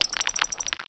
sovereignx/sound/direct_sound_samples/cries/dwebble.aif at master
dwebble.aif